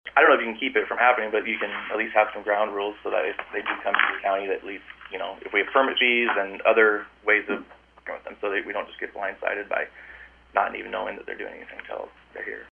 Board Chair Matt Wedemeyer said the Board can’t prevent it, especially if officials with Navigator CO2 Ventures, which has proposed the project, decides to execute the process of Eminent Domain.